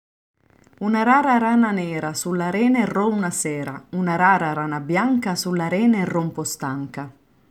But today I propose to you a few Italian tongue-twisters read by some foreigners who live in Oslo, because I would like you to listen to their different accents and underline the beauty of each.